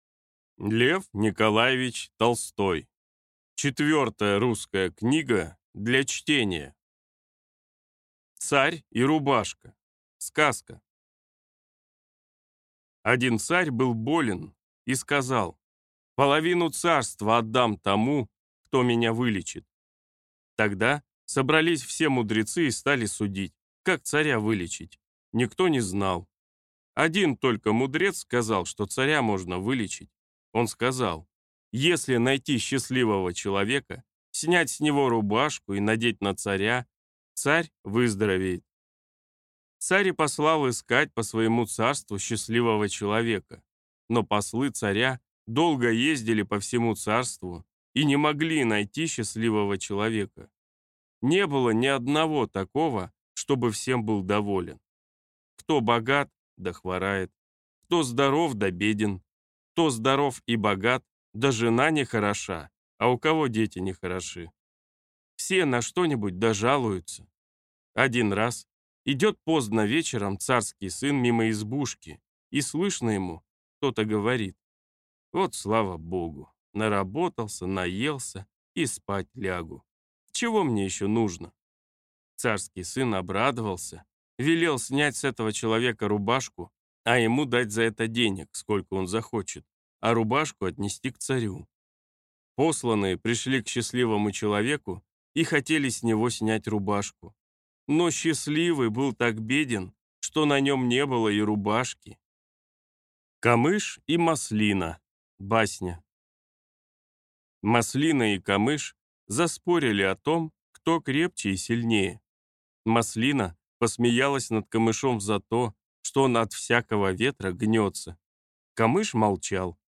Аудиокнига Четвертая русская книга для чтения | Библиотека аудиокниг